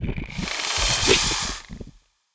Index of /App/sound/monster/misterious_diseased_bow
attack_act_1.wav